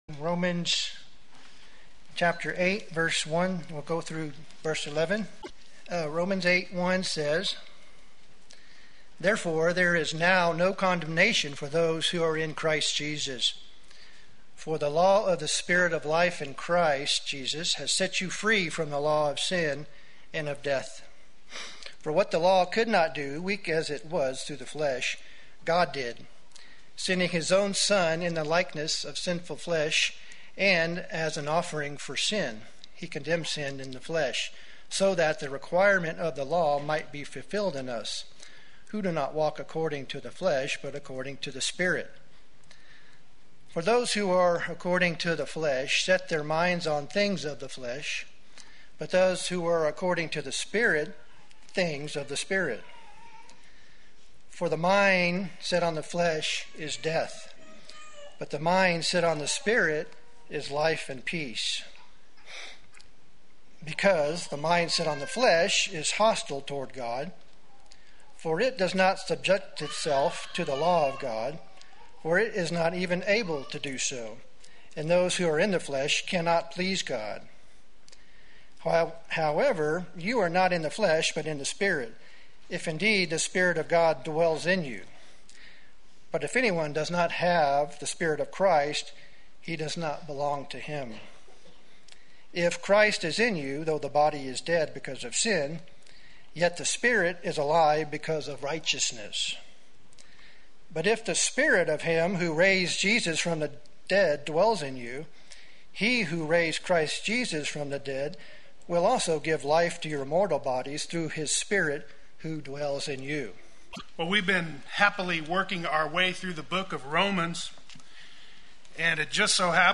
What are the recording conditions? Life in the Spirit Sunday Worship